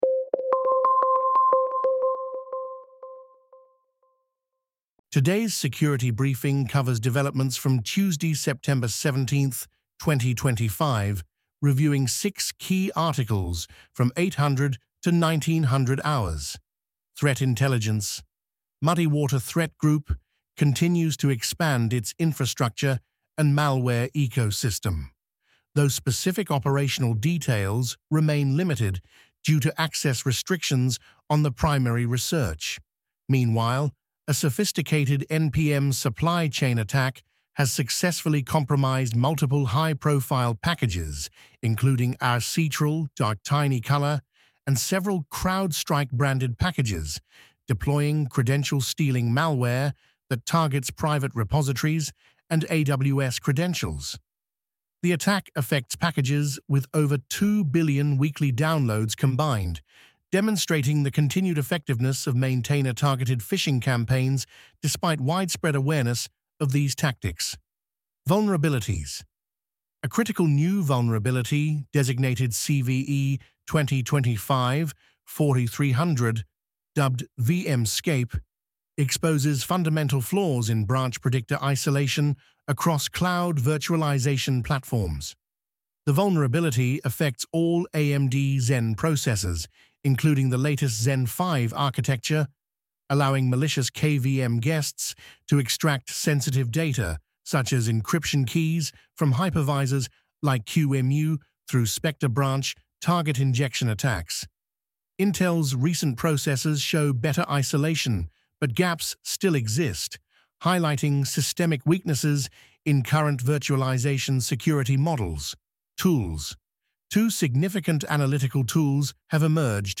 Daily InfoSec blue team security briefing for September 18, 2025